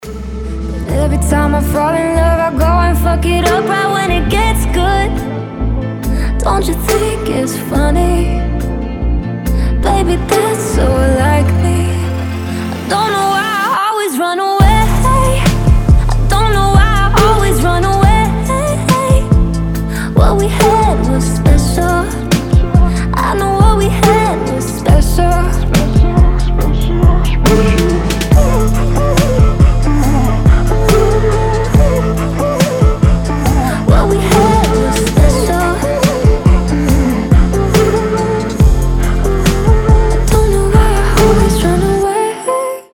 • Качество: 320, Stereo
поп
спокойные
медленные
красивый женский голос
Chill Trap
Bass